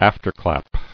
[af·ter·clap]